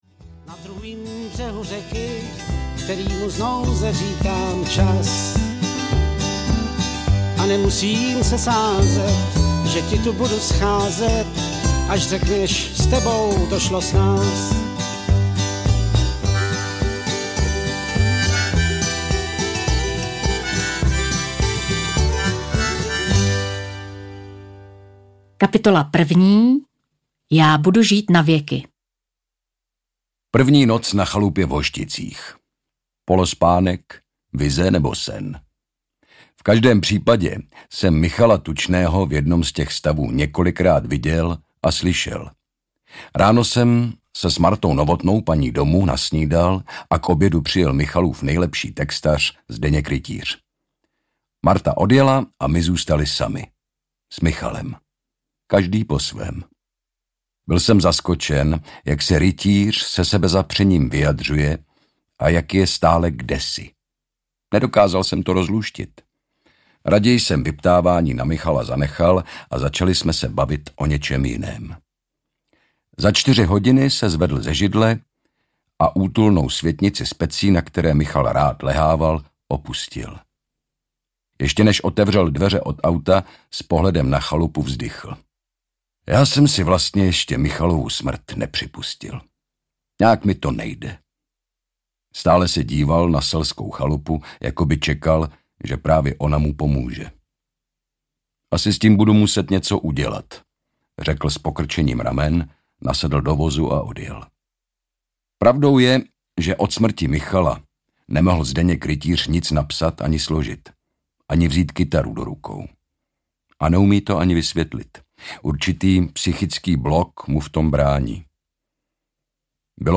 Audiokniha Michal Tučný - A vzpomínky ty nemůžeš si zout, kterou napsali Marta Novotná a Rostislav Sarvaš.
Ukázka z knihy